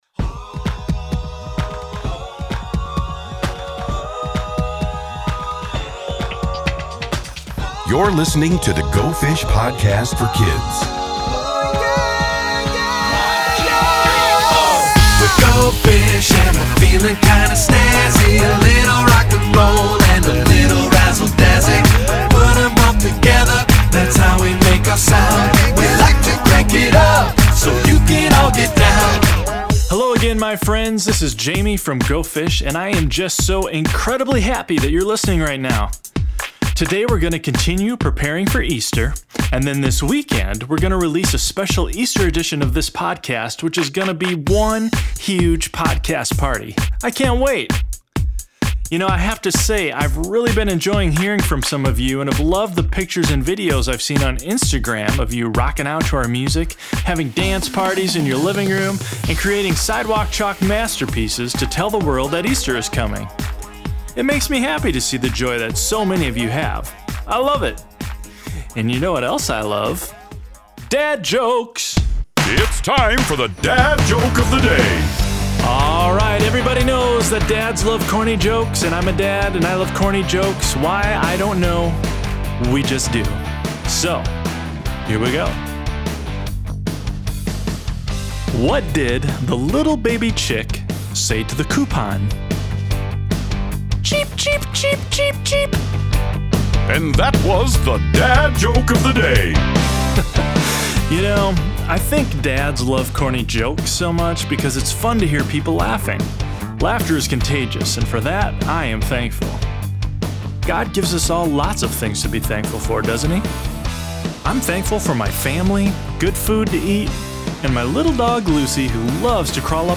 Go Fish uses music and Scripture to get children ready to celebrate Easter!